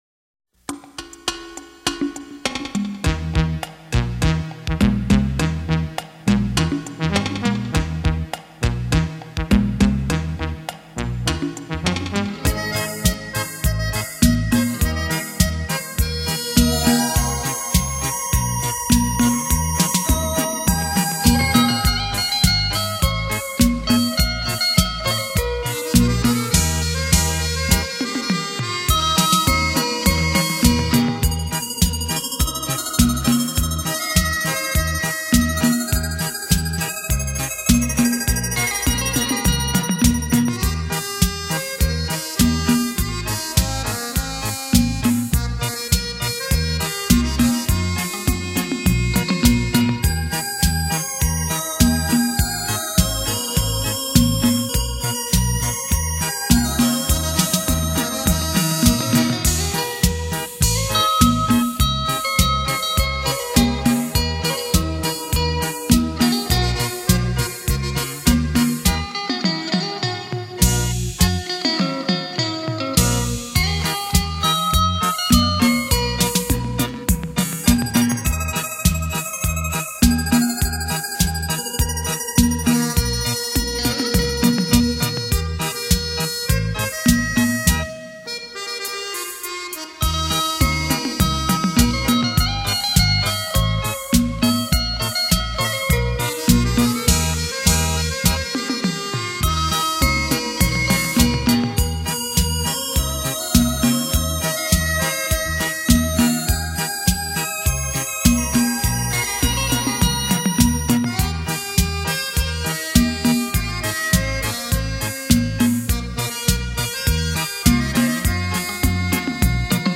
超级立体音场环绕 梦幻乐音